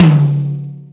• Long Tail Reverb Tom One Shot D Key 19.wav
Royality free tom drum single hit tuned to the D note. Loudest frequency: 390Hz
long-tail-reverb-tom-one-shot-d-key-19-g9v.wav